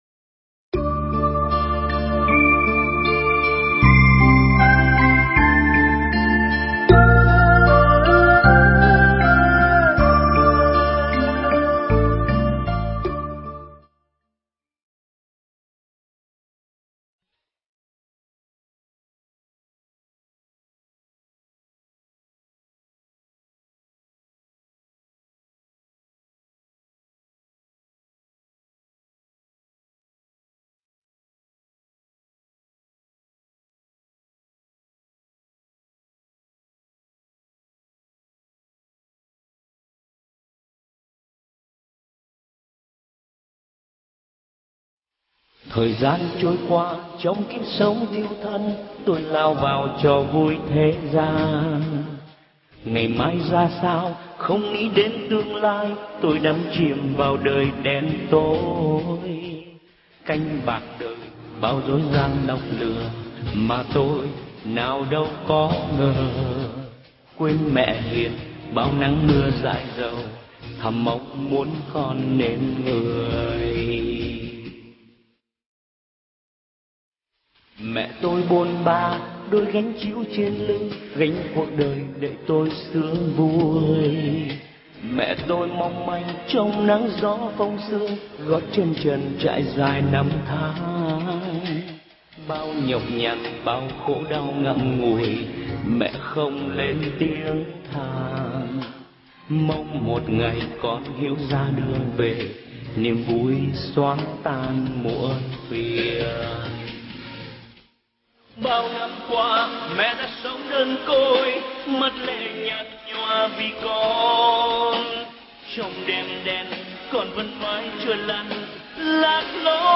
Nghe Mp3 thuyết pháp Những Mẩu Chuyện Qua Chuyến Hoằng Pháp Tại Úc Châu